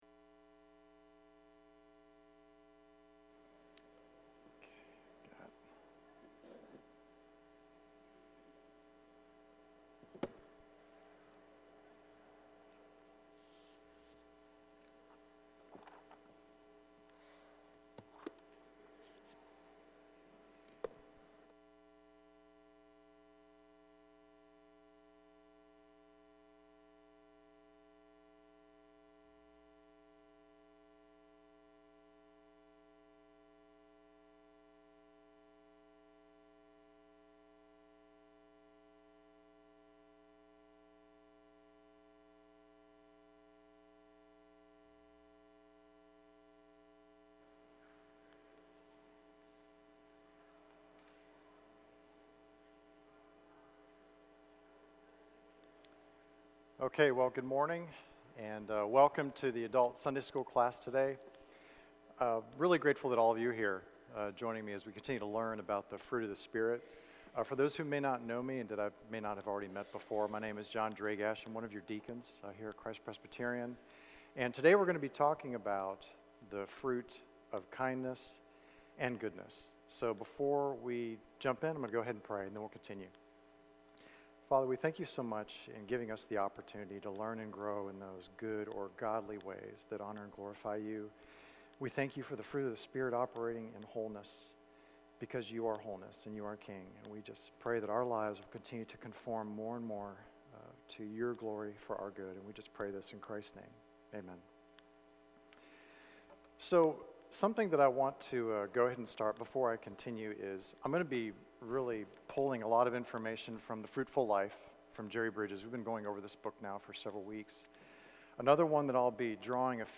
Sunday School Fruits of the Spirit Oct 15 2023